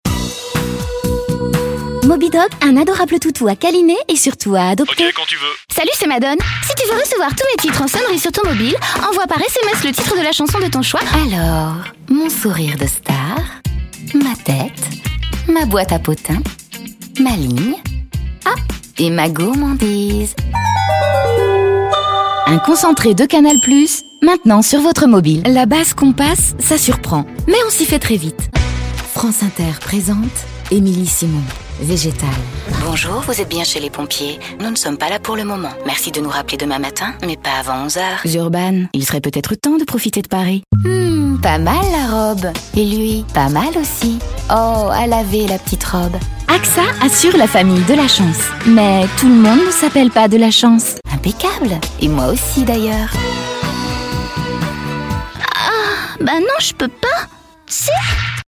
medley voix